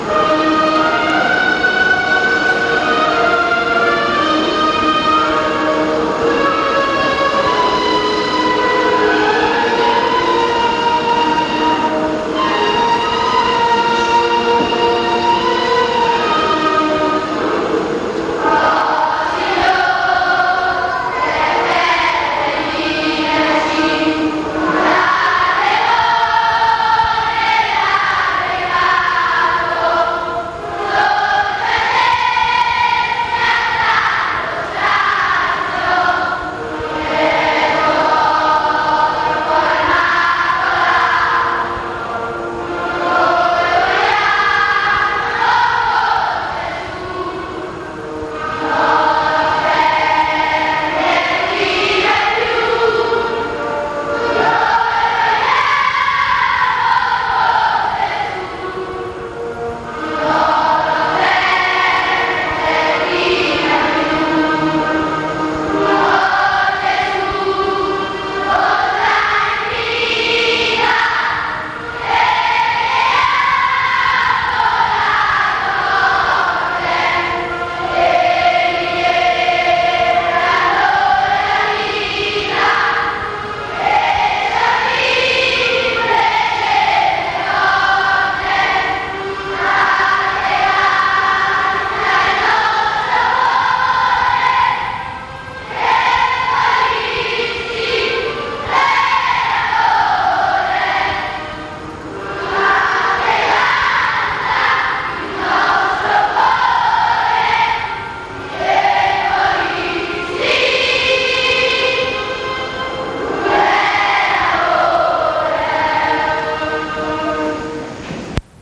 L'inno dell'Arciconfraternita della Morte ed Orazione in MP3
inno_morte.mp3